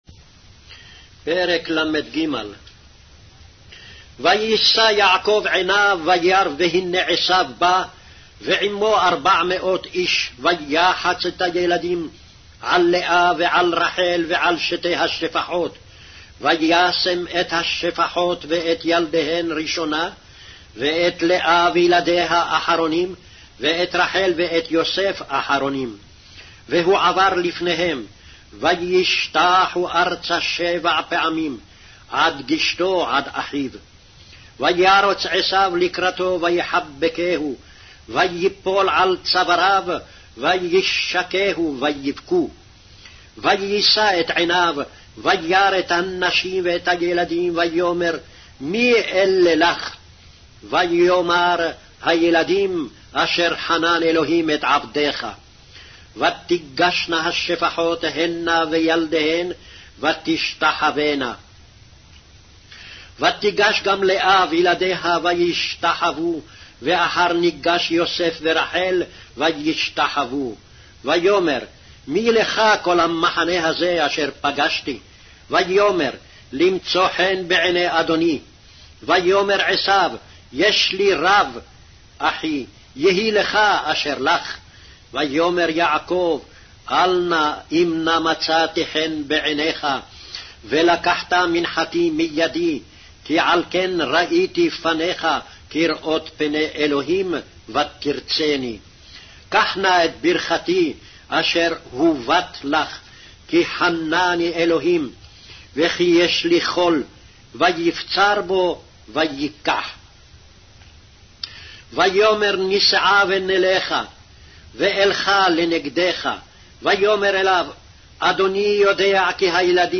Hebrew Audio Bible - Genesis 16 in Ervmr bible version